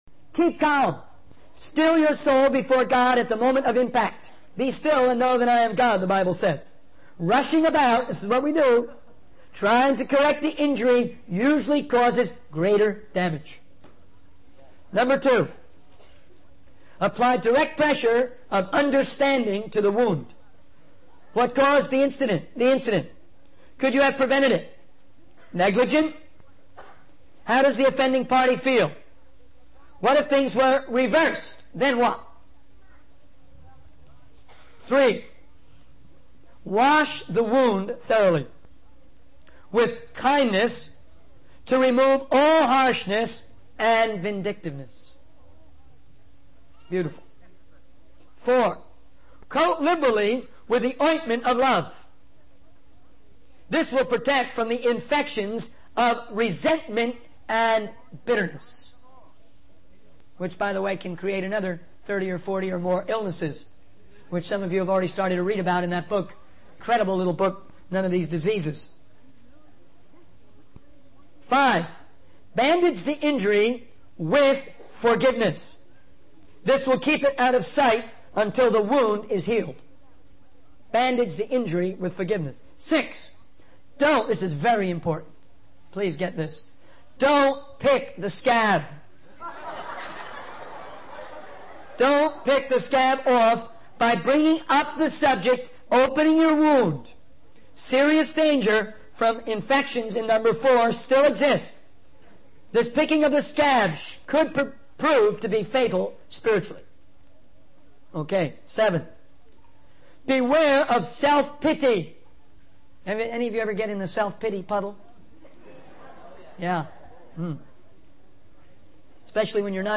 In this sermon, the preacher emphasizes the importance of spiritual balance in the lives of believers. He encourages the audience to seek a balance between enthusiasm and being easily led astray. The preacher also discusses the balance between crisis experiences and the process of growth in one's faith.